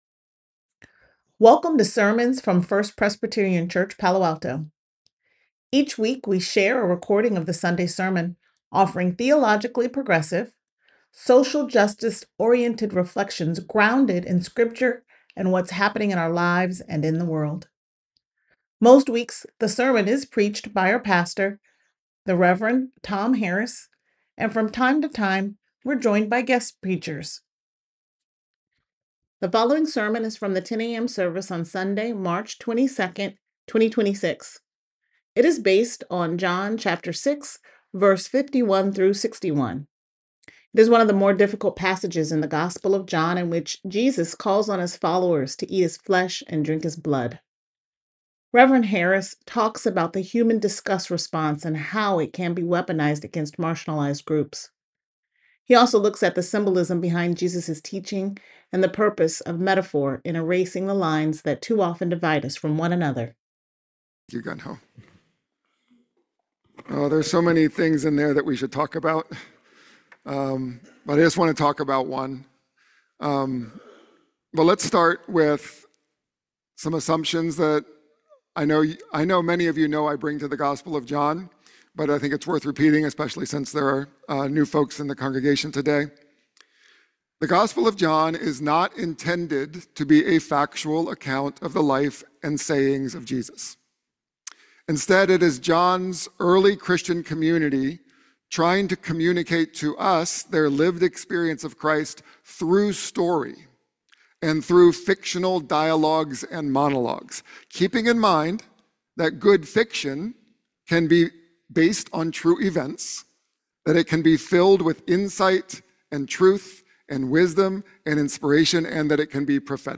The following sermon is from the 10 a.m. service on Sunday, March 22nd, 2026. It is based on John chapter 6 verses 51 through 61. It is one of the more difficult passages in the Gospel of John in which Jesus calls on his followers to eat his flesh and drink his blood.